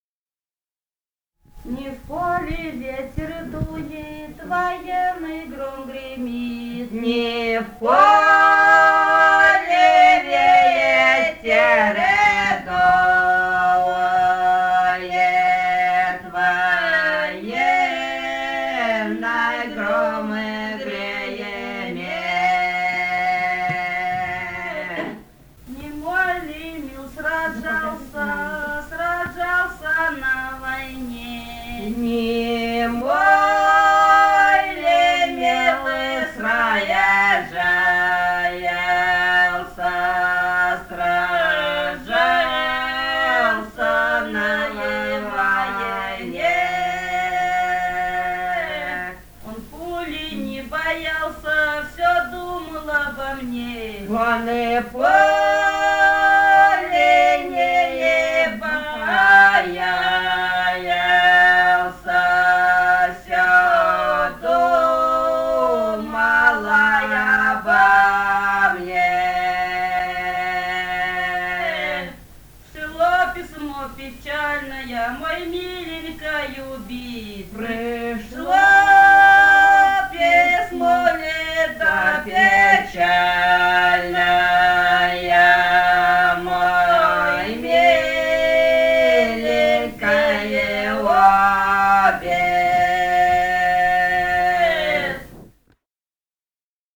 Этномузыкологические исследования и полевые материалы
«Не в поле ветер дует» (лирическая «казачья»).
Бурятия, с. Петропавловка Джидинского района, 1966 г. И0903-09